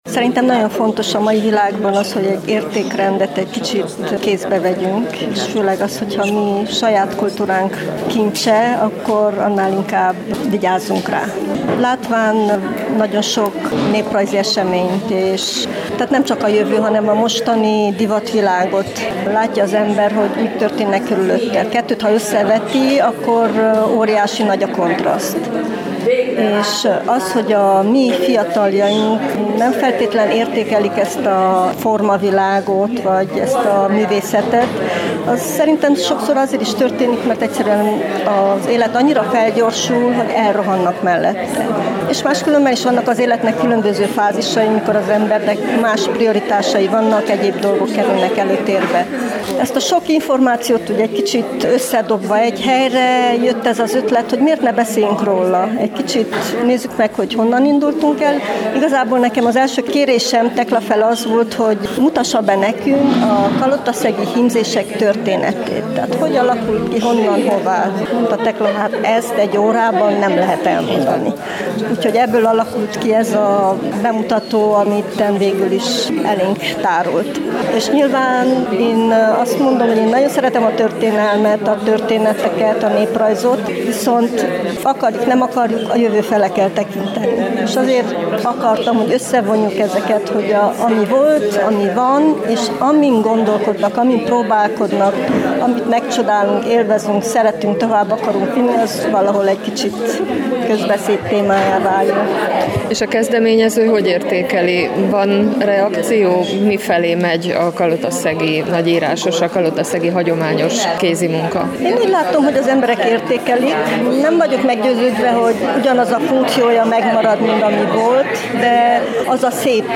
Mintákról, motívumokról, a nagyírásos történetéről is beszéltek a témában szervezett kolozsvári találkozón.